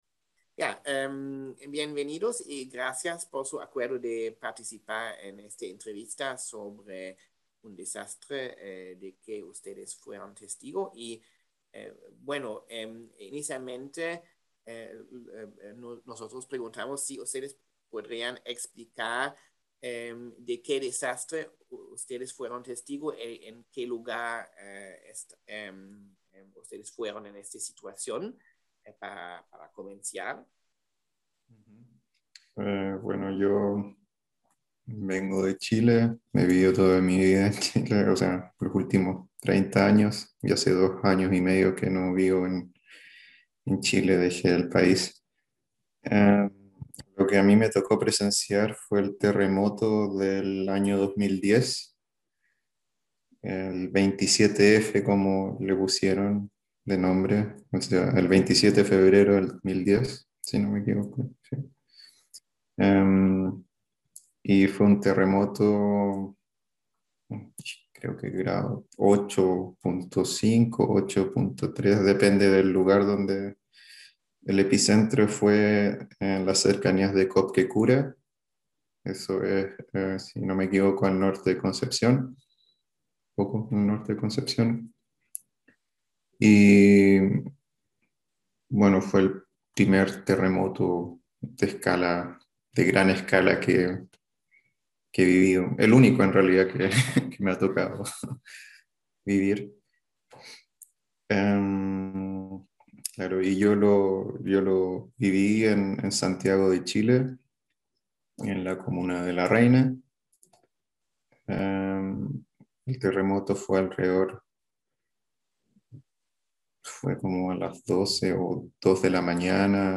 Entrevista Terremoto Chile – Citizen Science and Nature Based Solutions for improved disaster preparedness